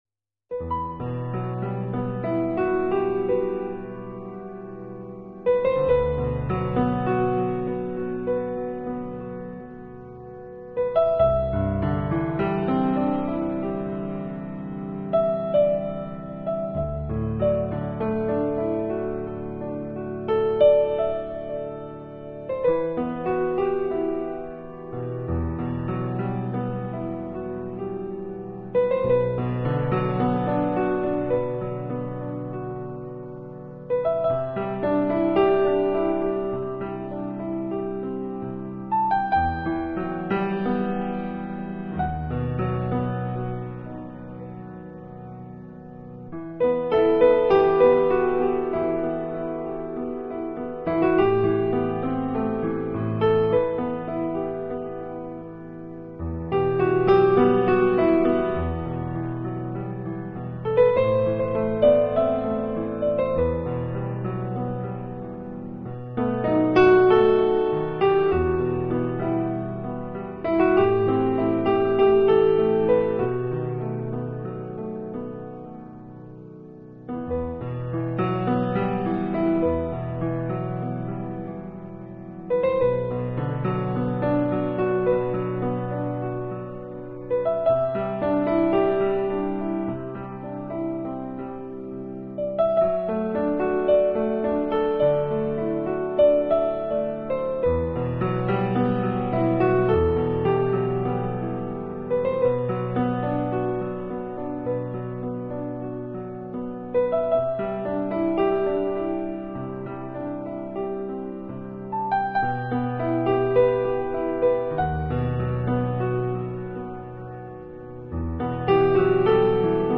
新世纪纯音乐